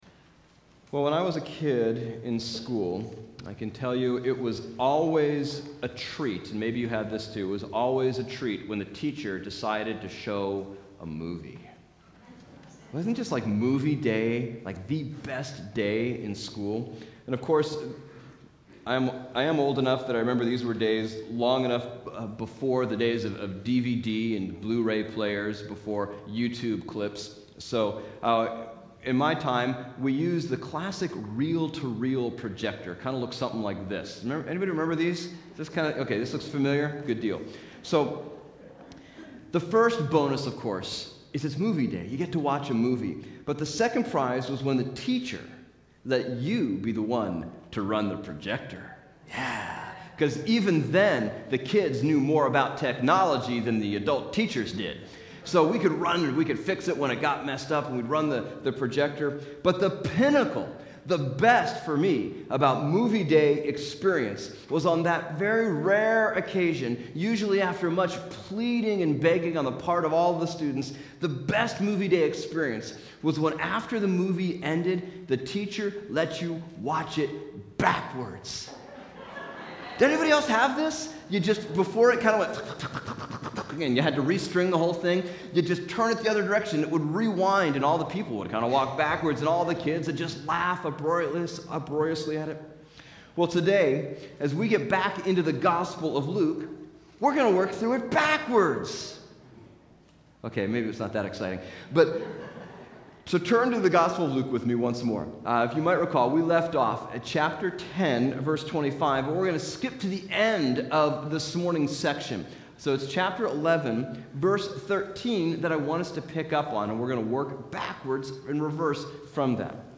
Bible Text: Luke 10:25-11:13 | Preacher